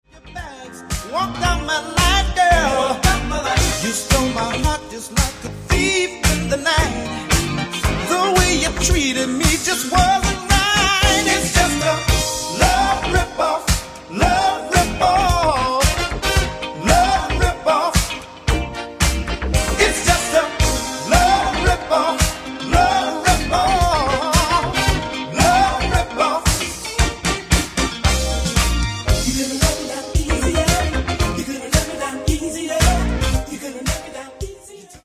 Genere:   Disco | Soul | Funk
12''Mix Extended